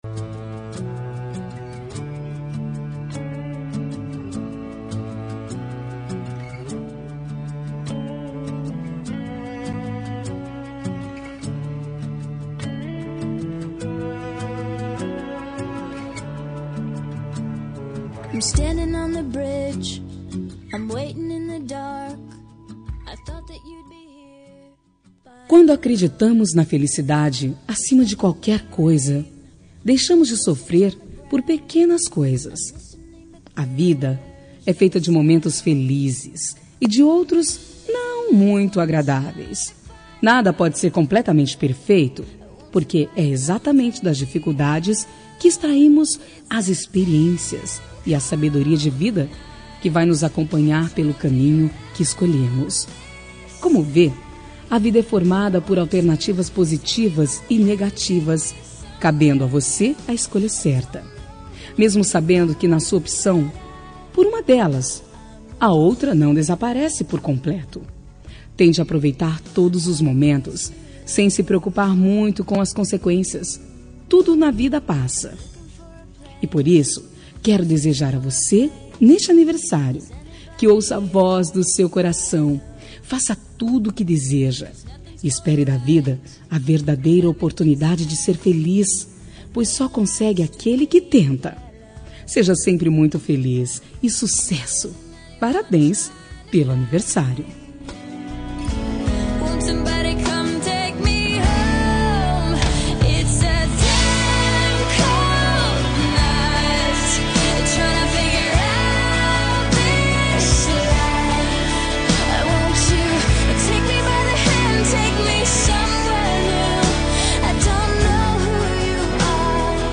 Aniversário de Pessoa Especial – Voz Feminina – Cód: 1887